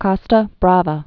(kŏstə brävə, kôstə, kō-, kōstä brävä)